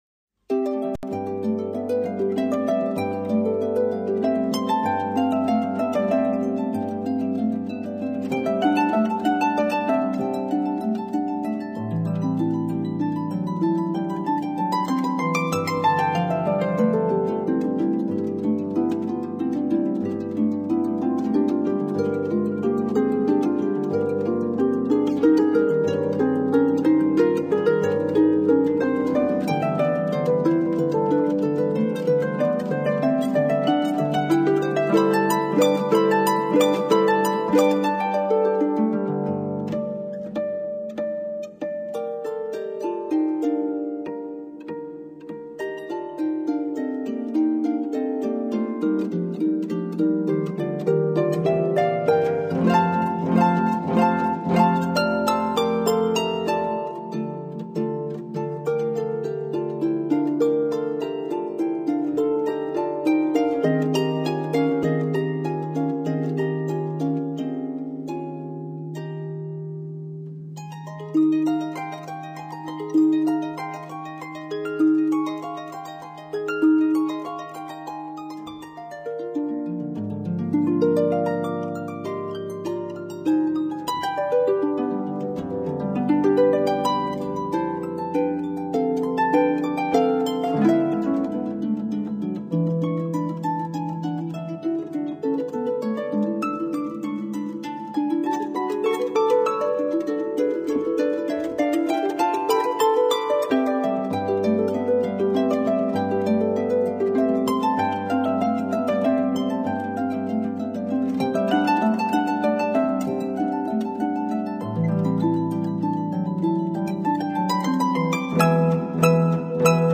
arpa